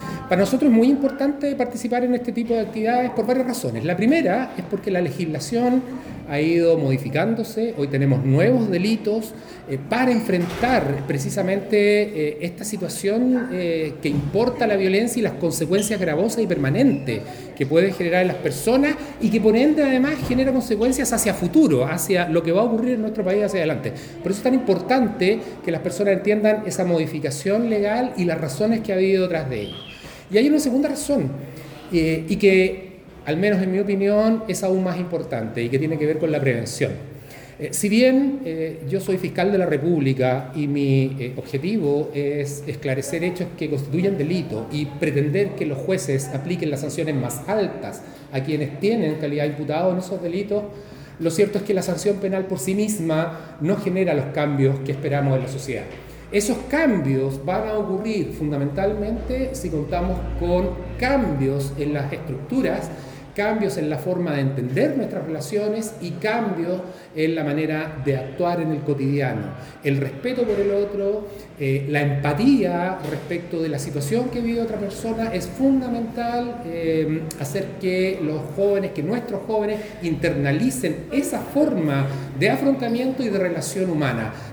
El Fiscal Enrique Canales valoró esta actividad que permite no solo informar a los jóvenes sobre los cambios en la legislación, sino que los interpela a actuar y prevenir desde sus propias realidades:
Fiscal-Enrique-Canales-por-seminario-jovenes.mp3